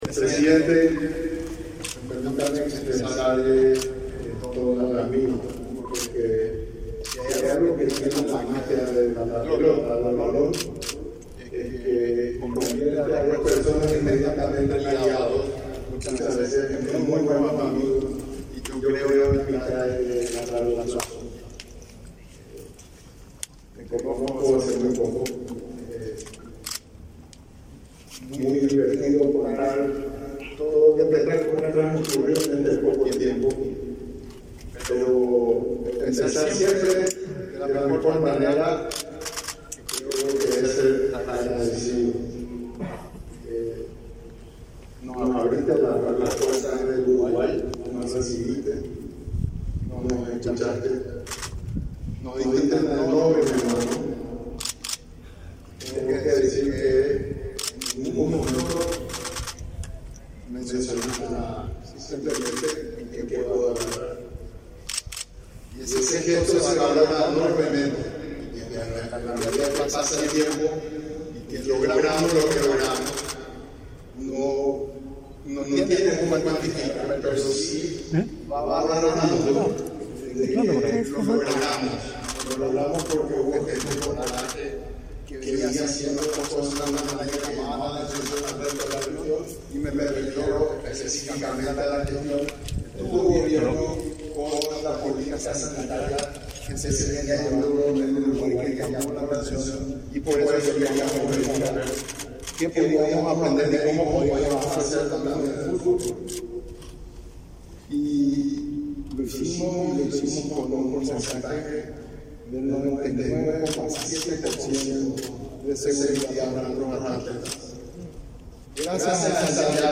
Palabras del presidente de la Conmebol, Alejandro Domínguez
El titular de la Conmebol, Alejandro Domínguez, disertó en el acto.